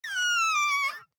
nutria-v2.ogg